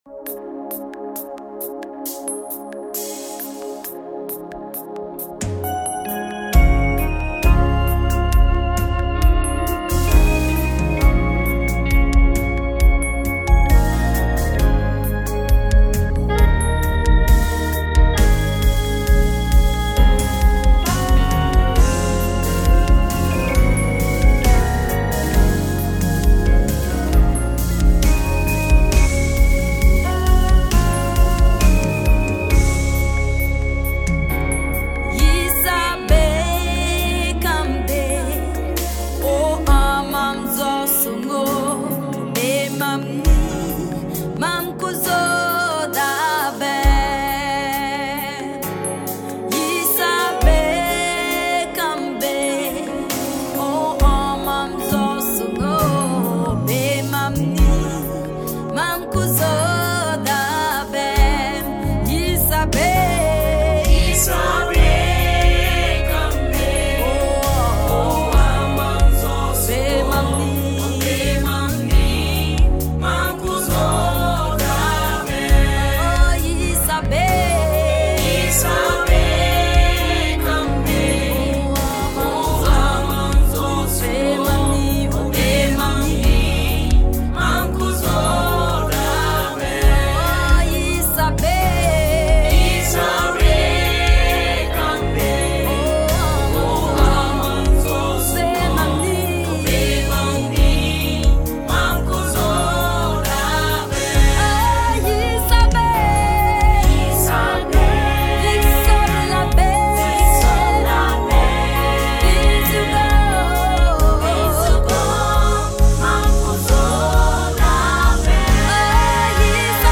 a masterful blend of traditional and contemporary sounds